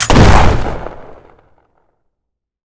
gun_shotgun_shot_01.wav